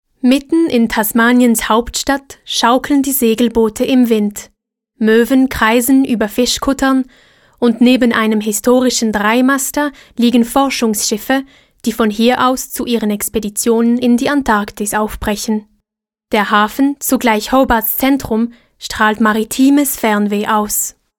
Narrative Documentary DE
Calm documentary introduction about Hobart, Tasmania. High German with Swiss accent.